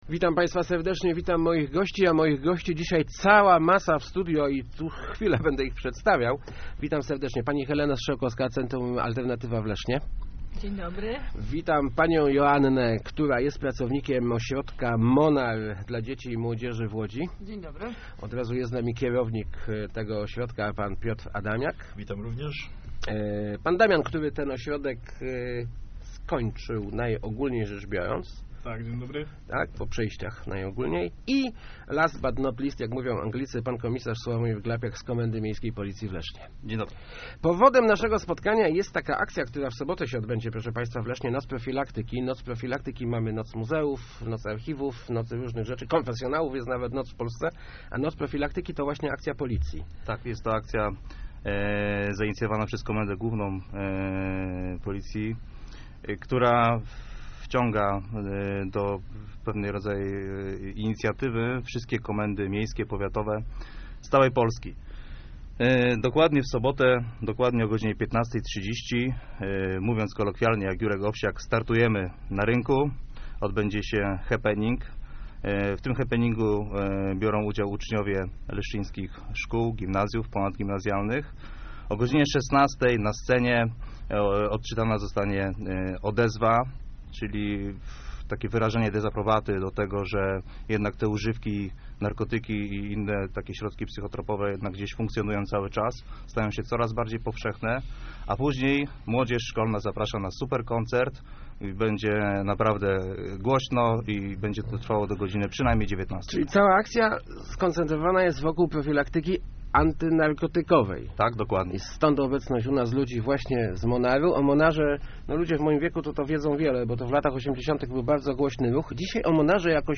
W sobot� 22 czerwca na leszczy�skim Rynku odb�dzie si� Noc Prewencji - ogólnopolska akcja policji maj�ca na celu zwrócenie uwagi m�odzie�y na niebezpiecze�stwa zwi�zane z narkotykami. -Nie ma bezpiecznych narkotyków, nie ma te� problemu z ich zdobyciem - mówili w Radiu Elka przedstawiciele Monaru i Alternatywy, którzy uczestnicz� w tej akcji.